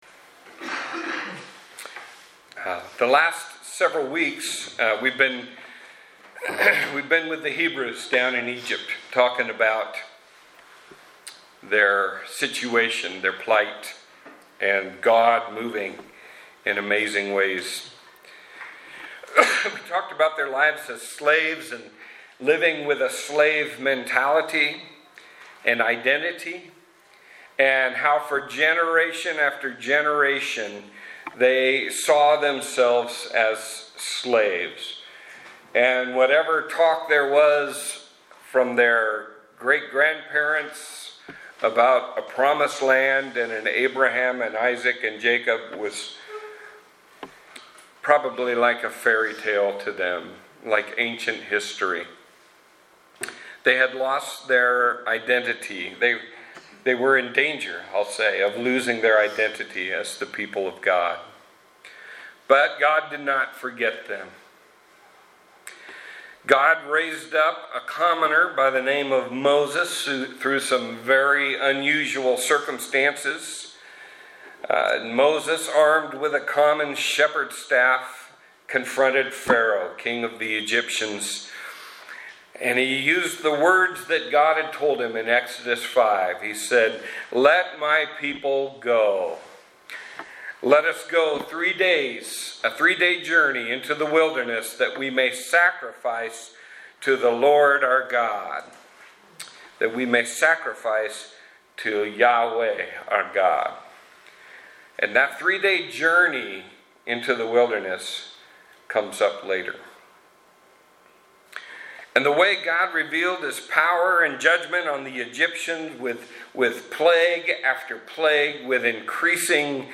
WestSide Community Church » Blog Archive » Sermon – 10/21/2018 – The Eastern Shore